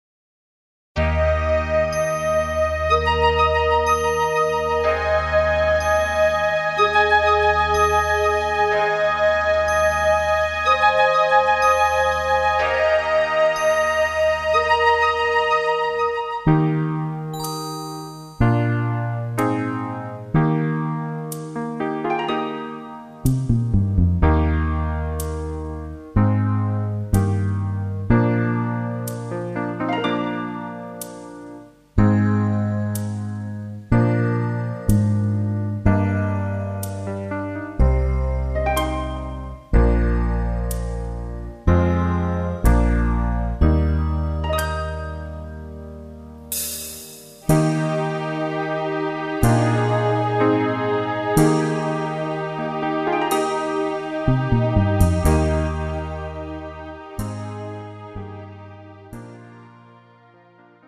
MR 고음질 반주